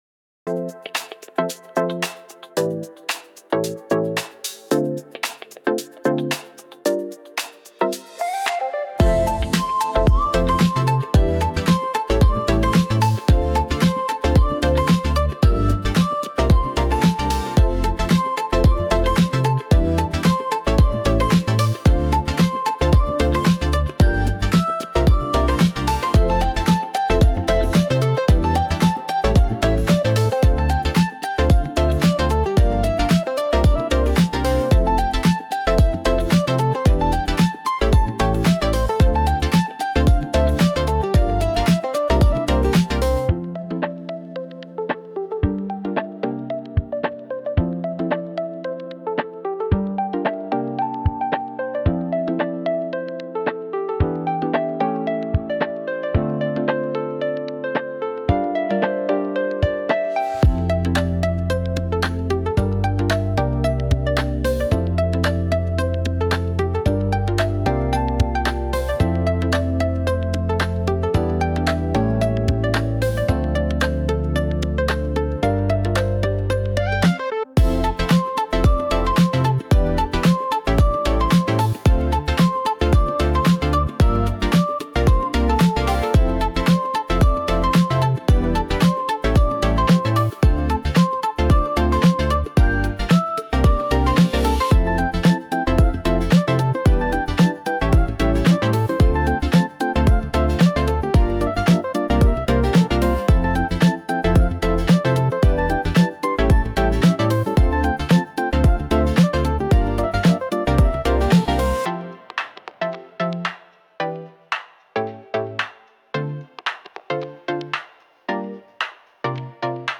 ケアフリーチルポップ・ボーカル無し
インストゥルメンタル